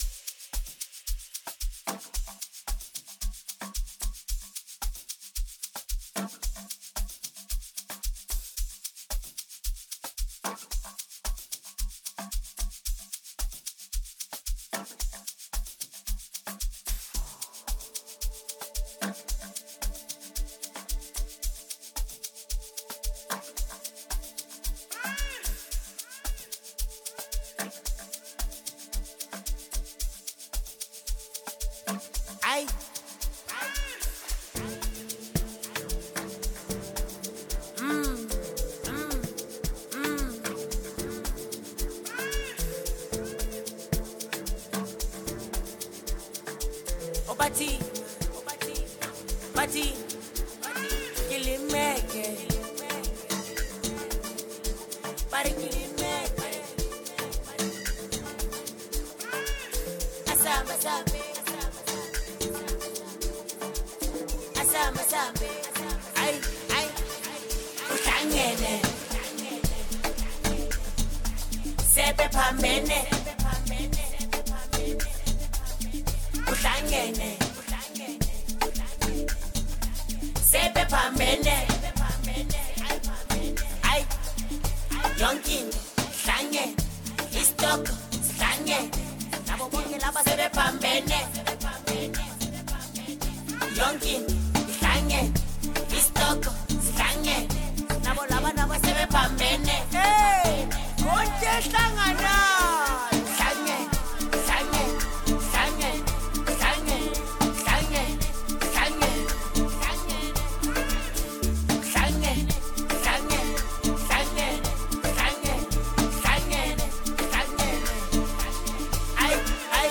This is a catchy tune, this new hit.